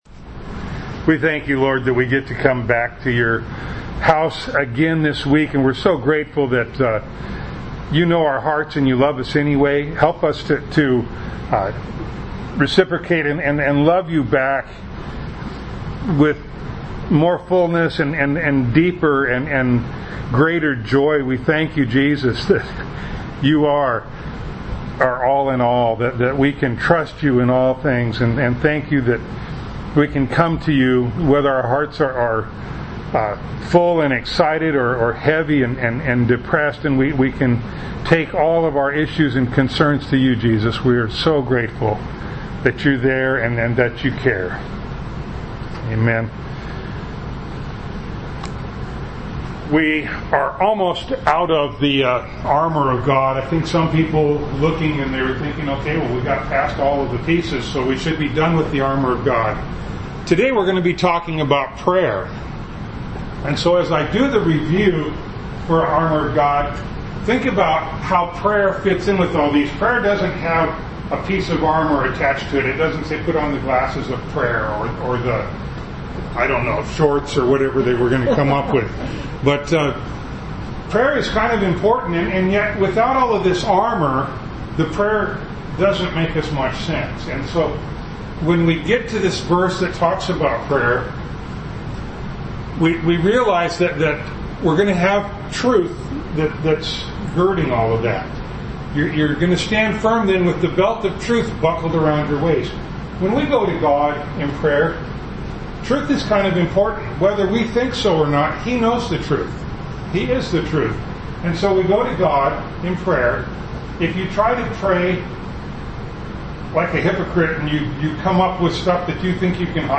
Ephesians Passage: Ephesians 6:18a Service Type: Sunday Morning Download Files Notes «